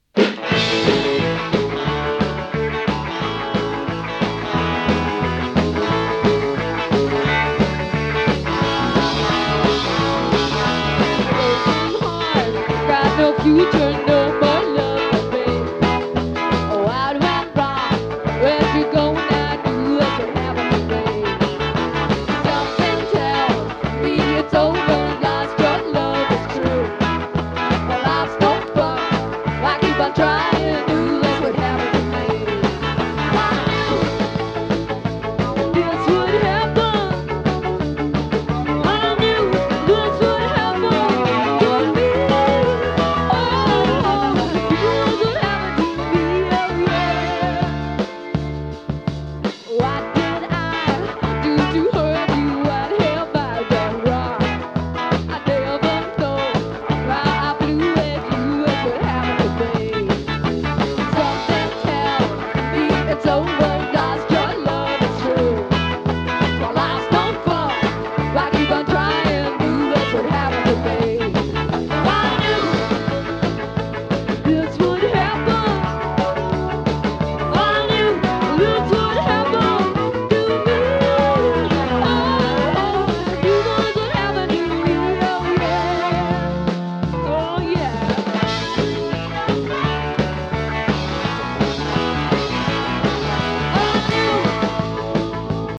豪州ジャイヴ ネオロカ